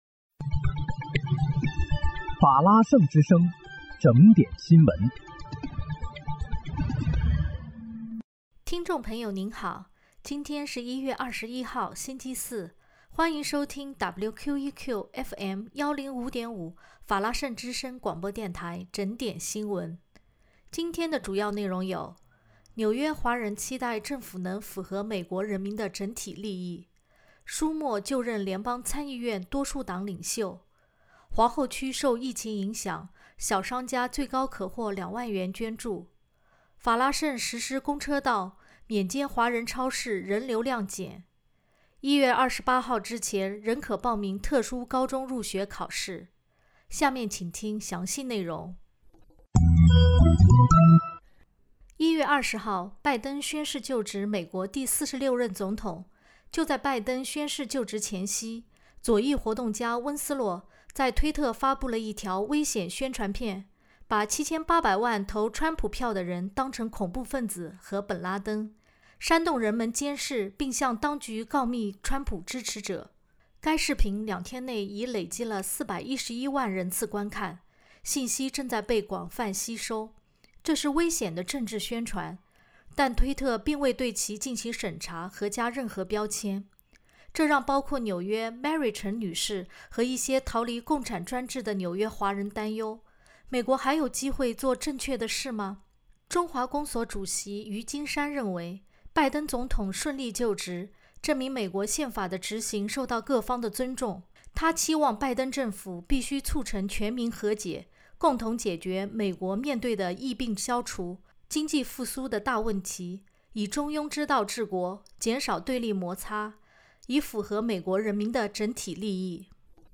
1月21日（星期四）纽约整点新闻